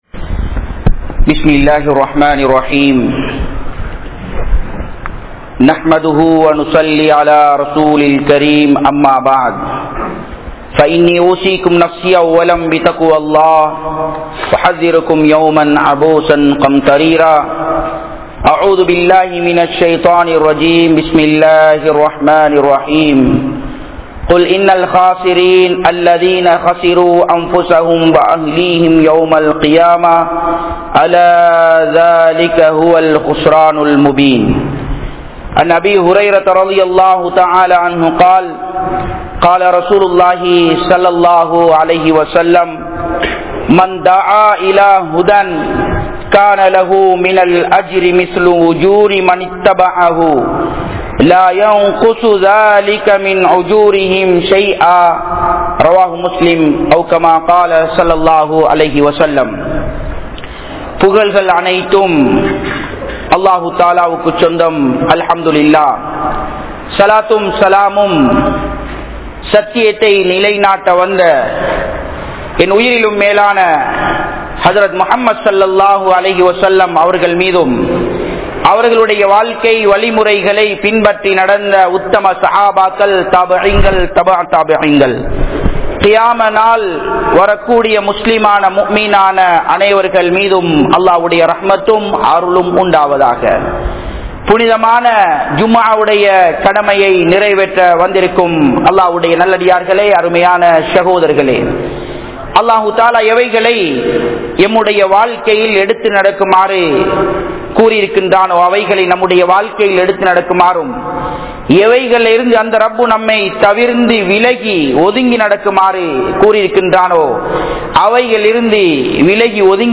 Paavaththil Perumai Adippavarhal (பாவத்தில் பெருமை அடிப்பவர்கள்) | Audio Bayans | All Ceylon Muslim Youth Community | Addalaichenai